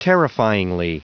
Prononciation du mot terrifyingly en anglais (fichier audio)
Prononciation du mot : terrifyingly